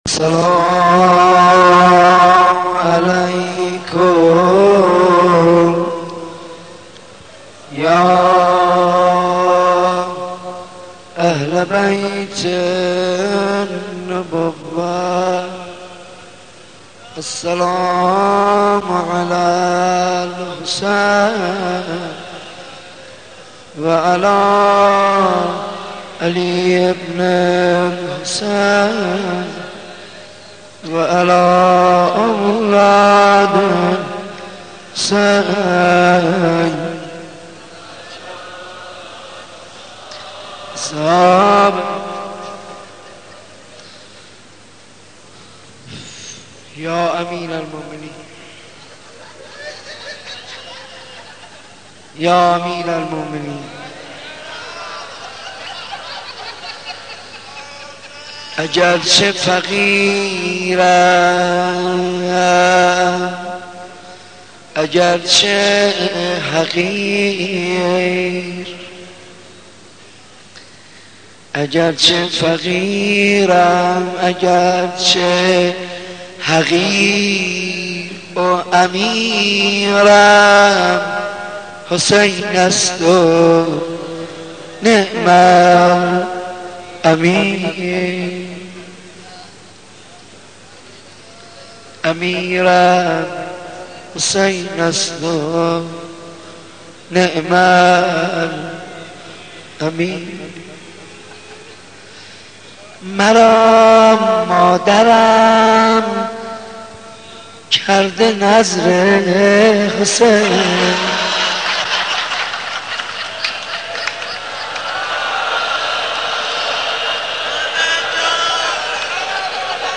مناسبت : شب هشتم محرم
مداح : حاج منصور ارضی قالب : مجلس کامل